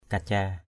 /ka-ca:/ 1.